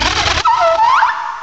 cry_not_wormadam.aif